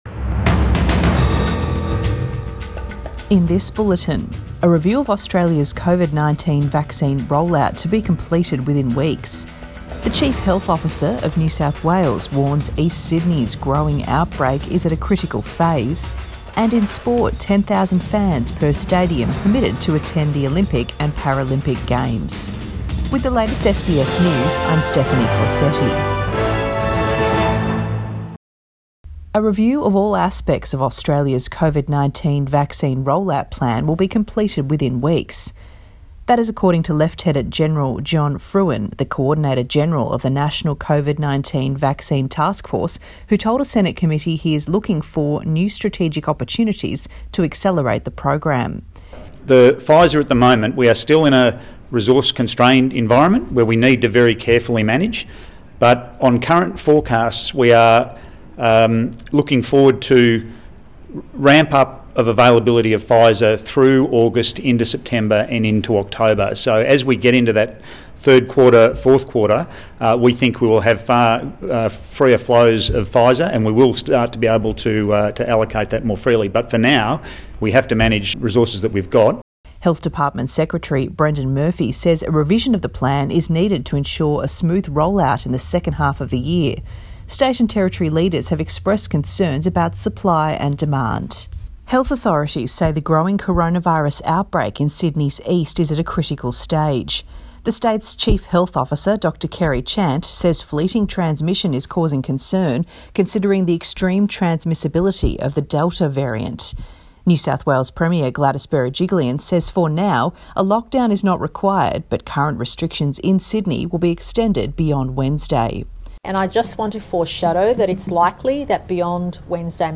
AM bulletin 22 June 2021